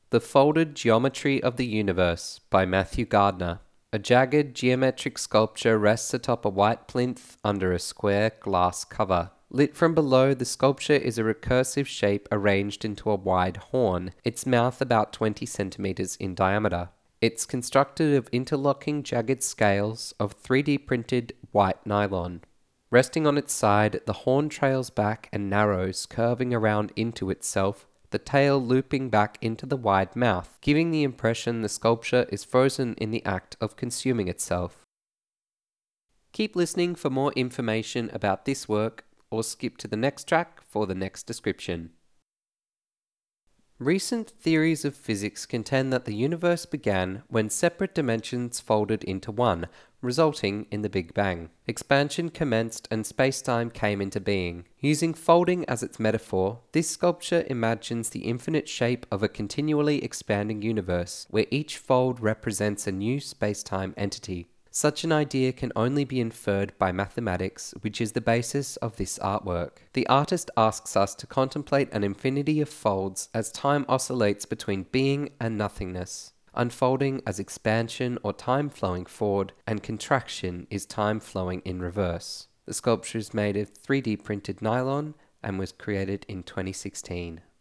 Presented as part of Experimenta Make Sense (2017-2021) Connect Enquiries to present this artwork Resources Audio Guide 'Folded Geometry of the Universe'